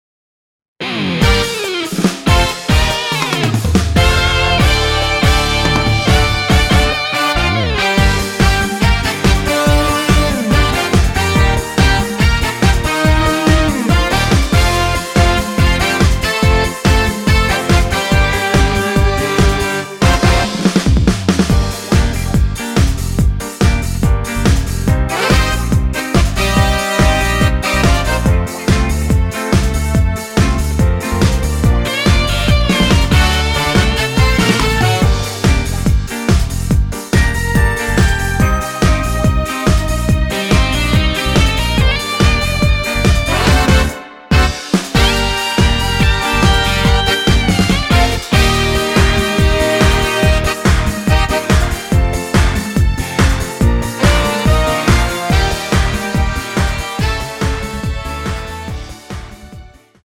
원키에서 (-2)내린 MR 입니다.(미리듣기 참조)
Gm
앞부분30초, 뒷부분30초씩 편집해서 올려 드리고 있습니다.